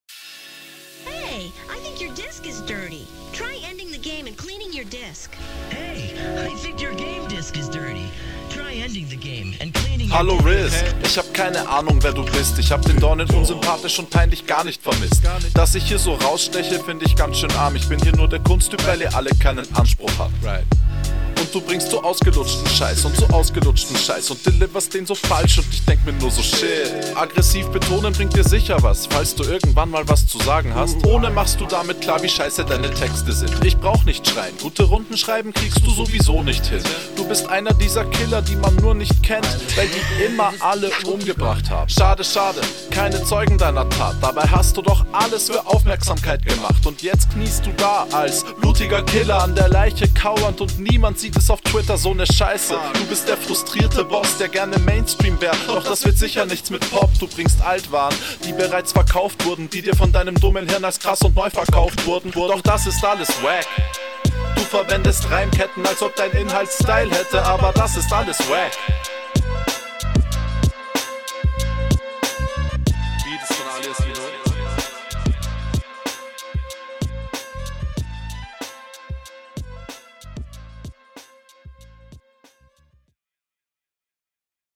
Sehr schöner Beat, die Atmo der Runde ist ziemlich killa.
Sehr geiler Beat mit dem switch oder hook?, dein Soundbild ist hier aufjedenfall richtig gut.